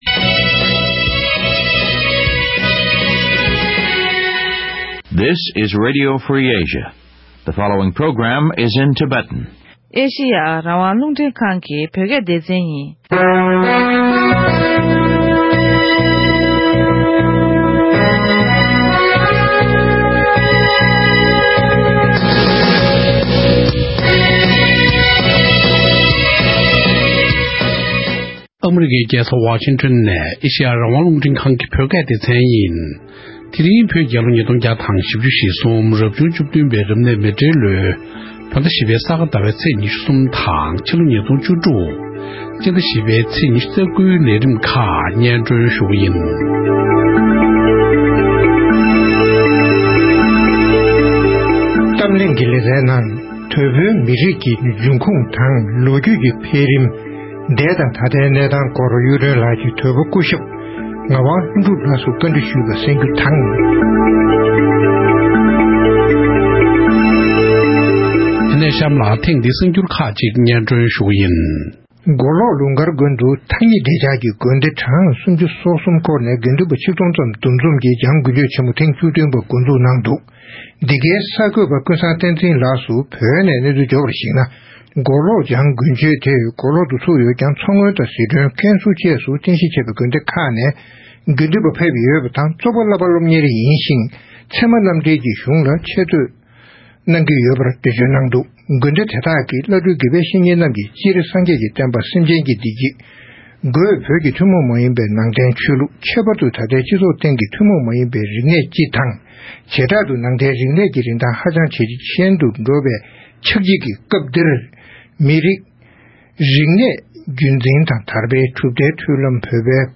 ༄༅༎ཐེངས་འདིའི་རེས་གཟའ་ཉི་མའི་གཏམ་གླེང་གི་ལེ་ཚན་ནང་།